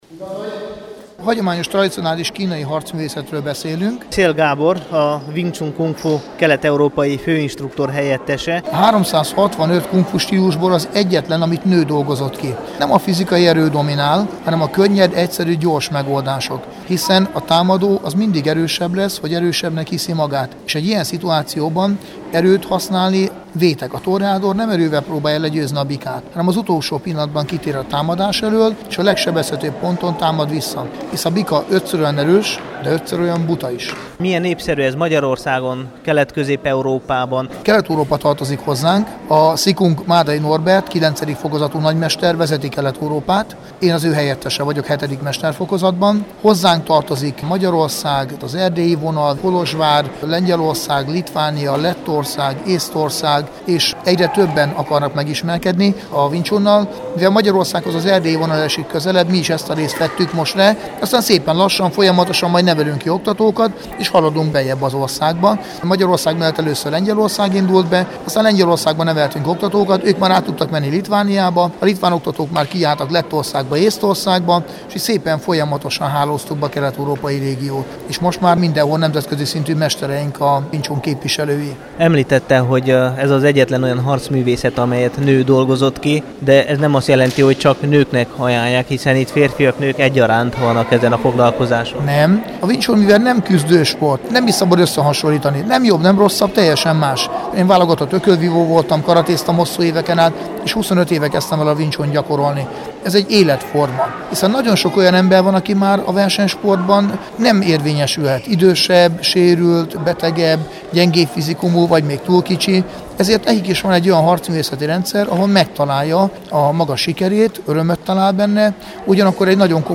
Merthogy videó nincs, csak audió, rádióriport.